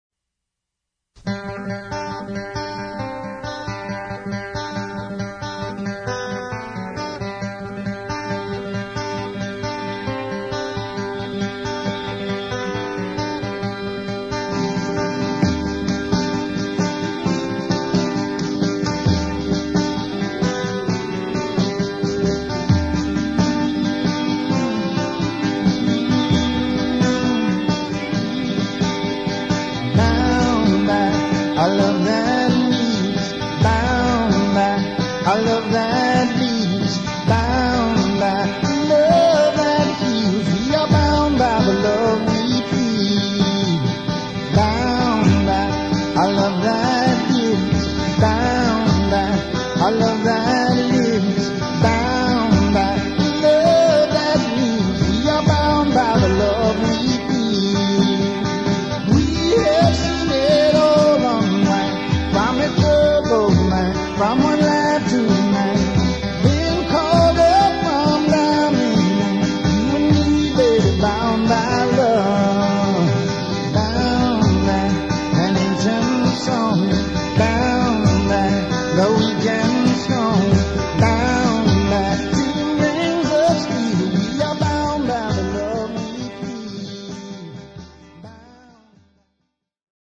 please note: demo recording
location: Nashville, date: unknown